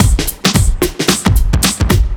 OTG_TripSwingMixC_110a.wav